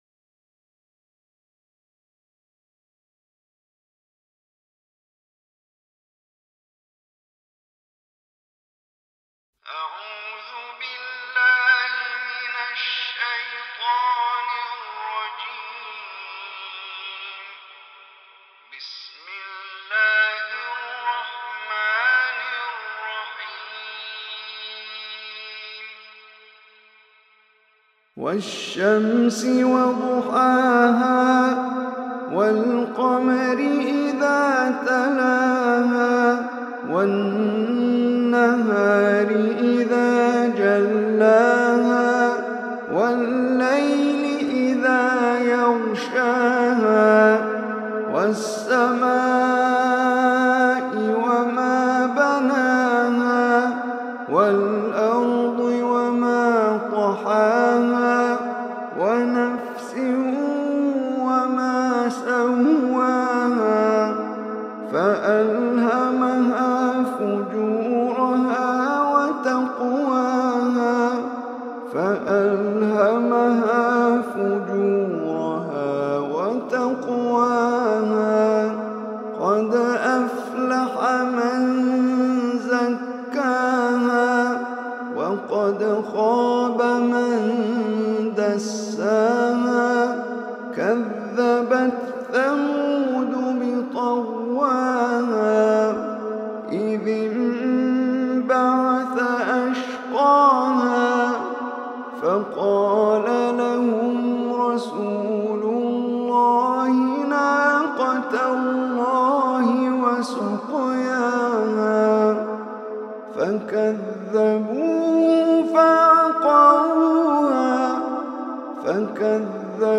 SURAH-ASH-SHAMS-POWERFUL-سورة-الشمس-عمر-هشام-العربي_3.mp3